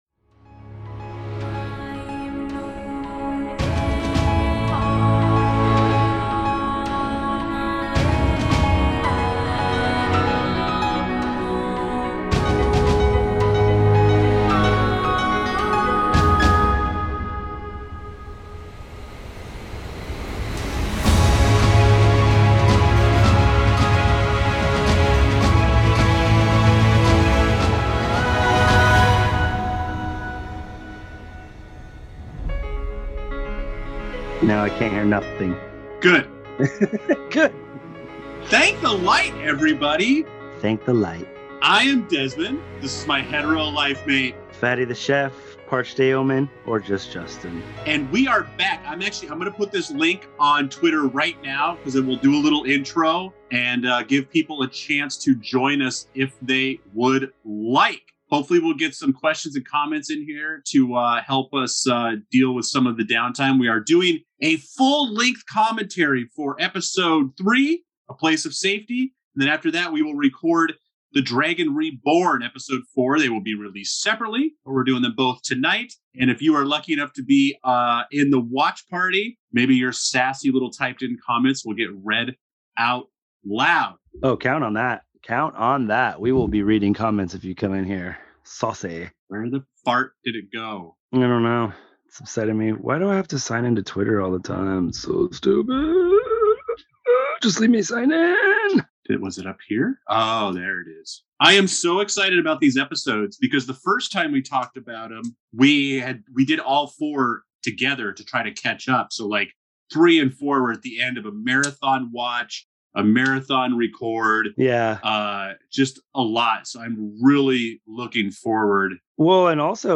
We filled the air with some words, but we kind of just enjoyed watching this episode together, we had a couple moments we failed to notice on the first viewing. This is a little bit of a breather episode after the splitting up of our heroes at the end of episode 2.